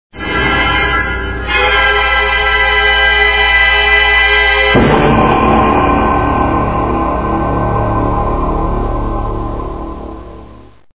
Ominous music.